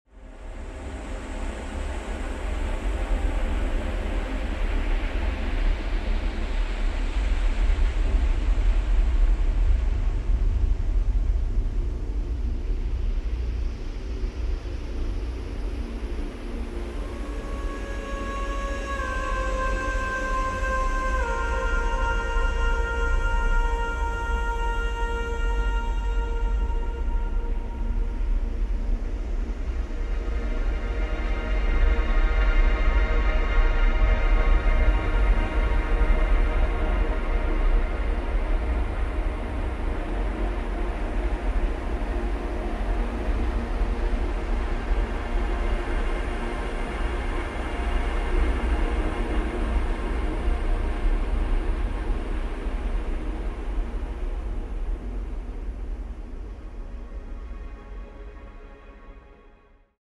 Deep Soundscape | Dark Ambient.